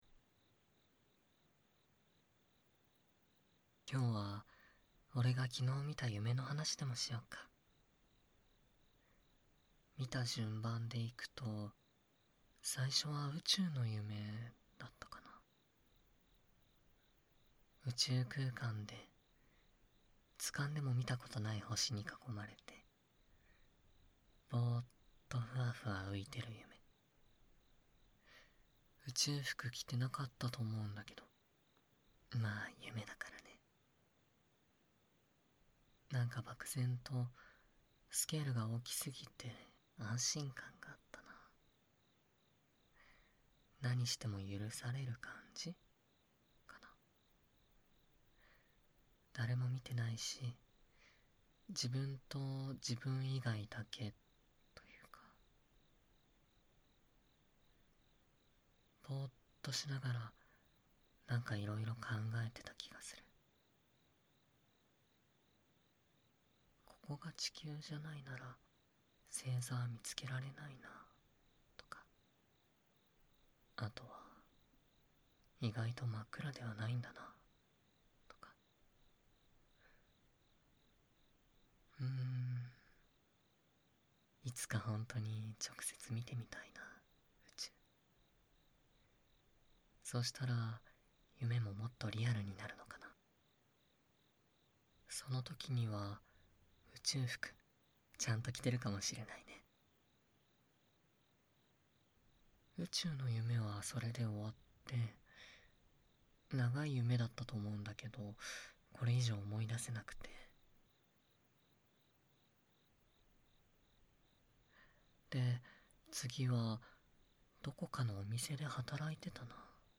【囁き・安眠】何気ない日の添い寝【催眠音声】
环绕音 ASMR